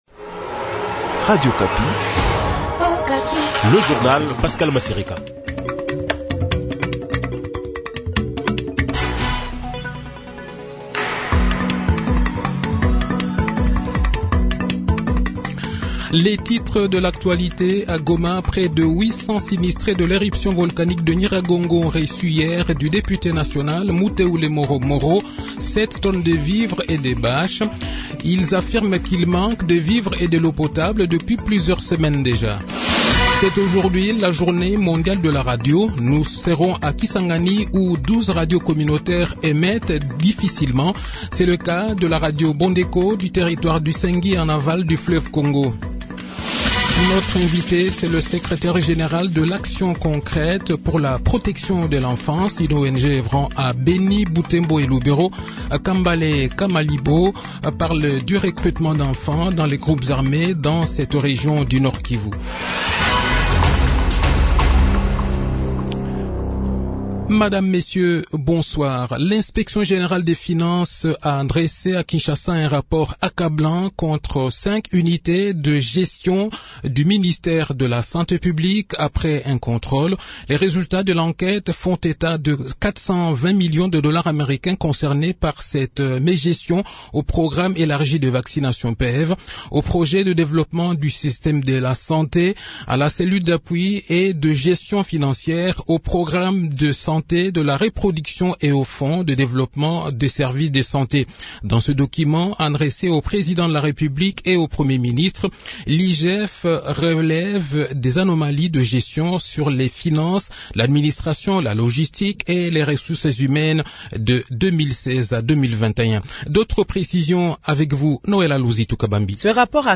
Le journal de 18 h, 13 février 2022